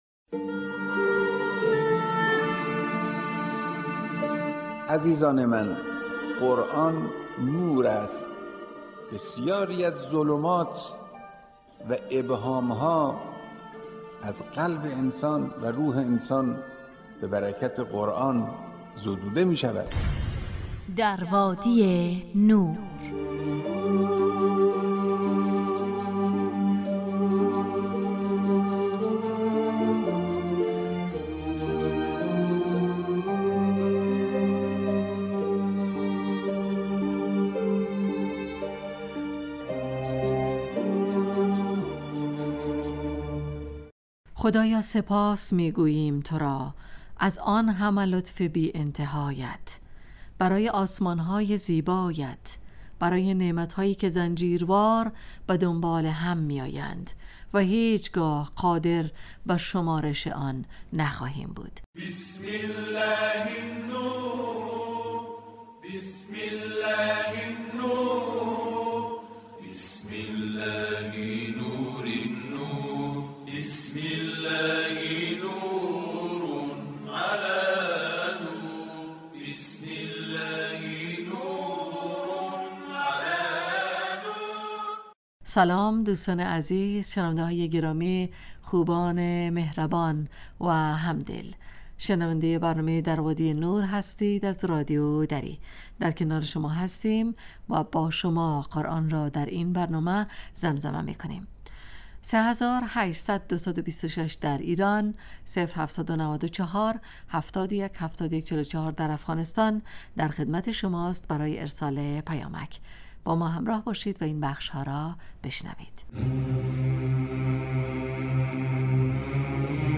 گویندگان: خانمها